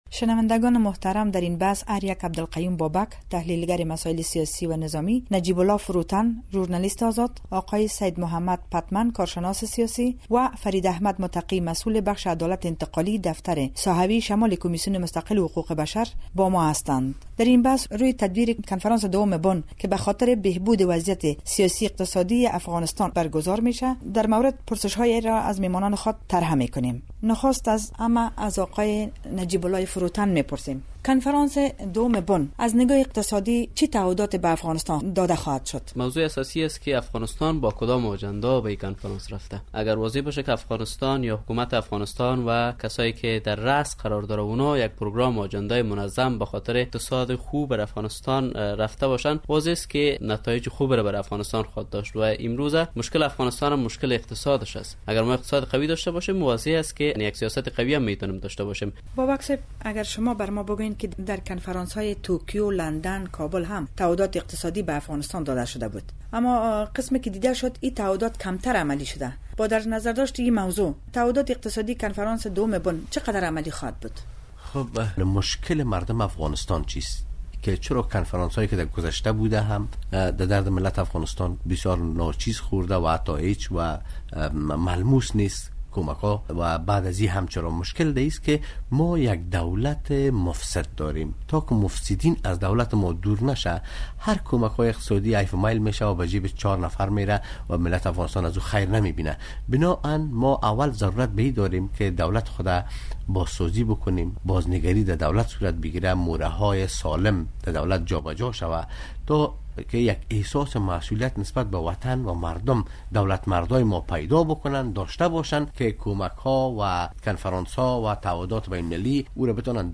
بحث میز گرد ولایت بلخ